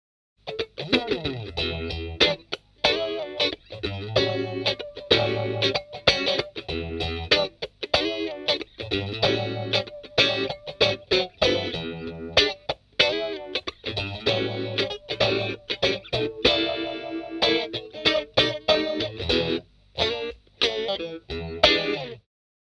Vintage style Phase Shifter
SE-VPH makes histolical PHASE SHIFTER Sound effect.
You can adjust resonance to control SHARPNESS of Phase Shift sound.
Demo with Single Pickup 2